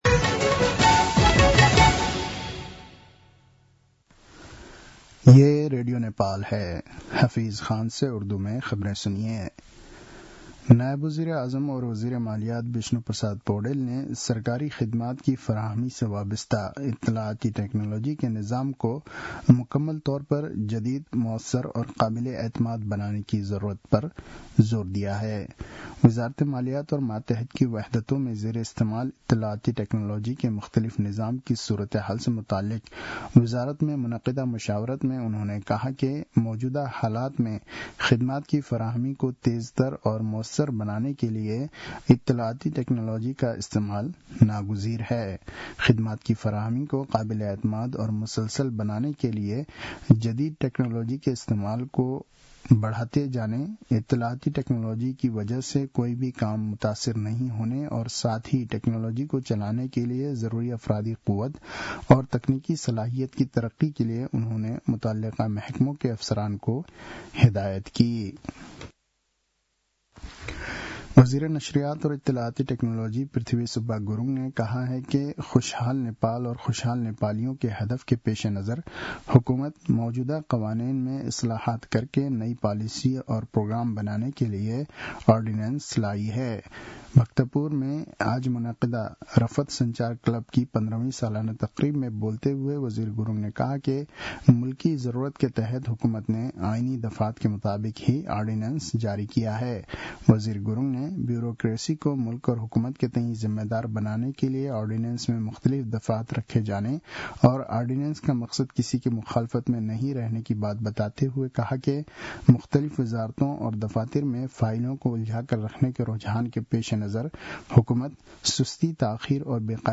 An online outlet of Nepal's national radio broadcaster
उर्दु भाषामा समाचार : २४ माघ , २०८१
Urdu-News-10-23.mp3